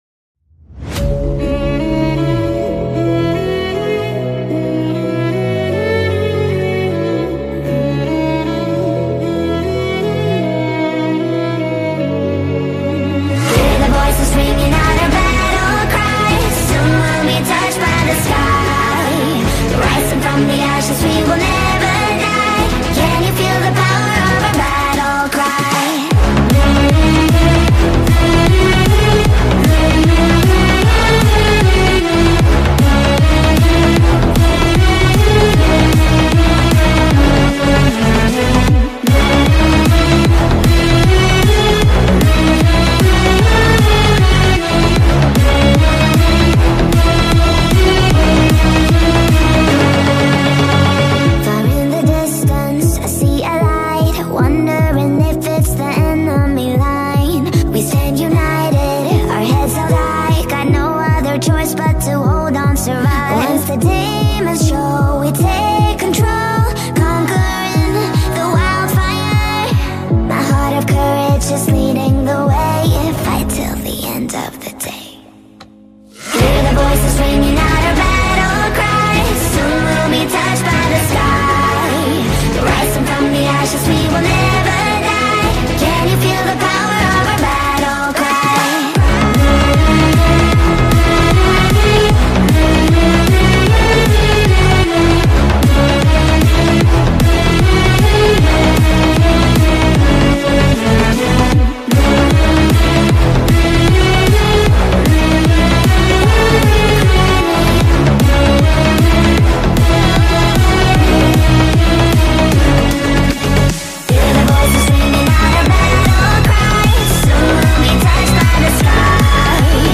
Amazing Sikorsky SH 60 Seahawk Helicopter Sound Effects Free Download